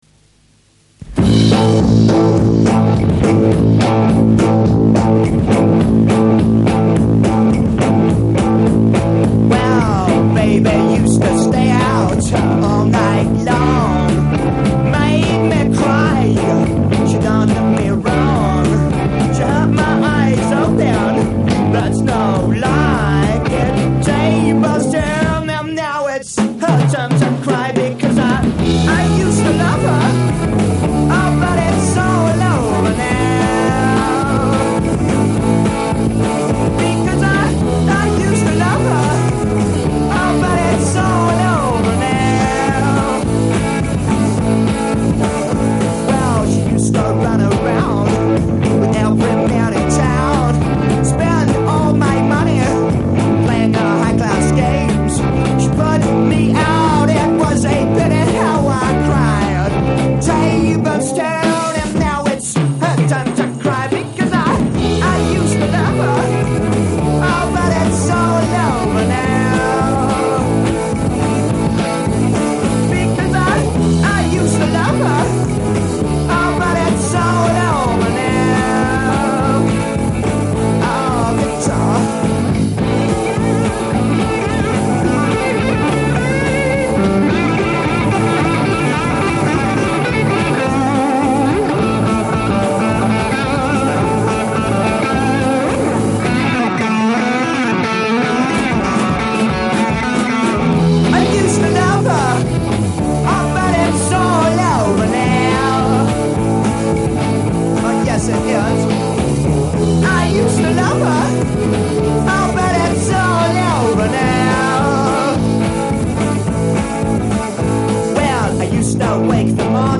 This was a loud kick-ass rock'n'roll band.